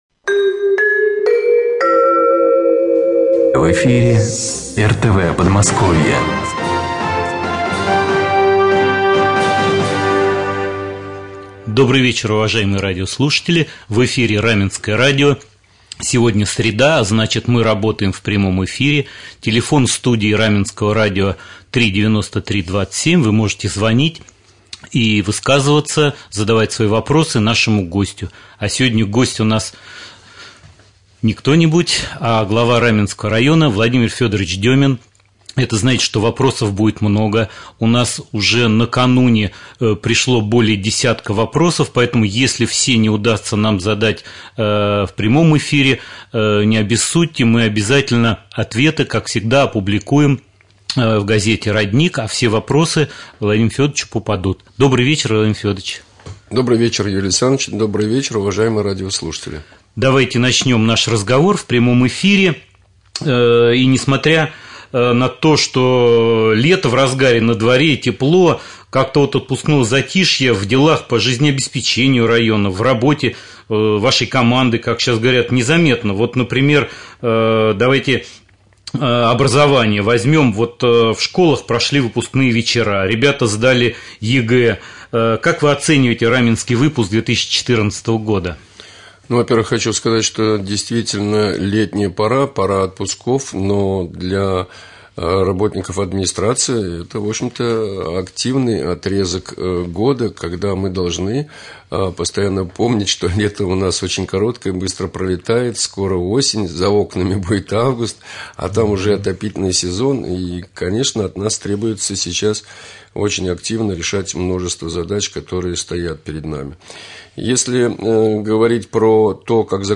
Прямой эфир. Гость студии глава Раменского муниципального района Владимир Федорович Демин.